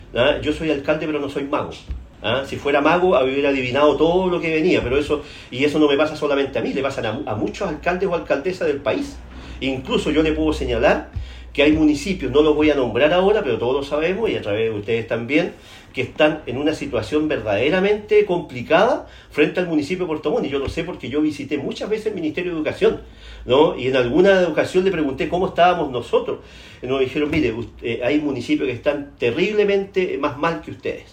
En el comedor de su casa, donde cumple la cautelar de arresto domiciliario total, con lápiz, papel y un vaso de agua, el exalcalde de Puerto Montt, Gervoy Paredes, conversó con Radio Bío Bío y defendió su inocencia afirmando que junto a su defensa tienen antecedentes que desestiman los cuatro delitos de corrupción que se le imputan al momento de liderar la capital regional de Los Lagos.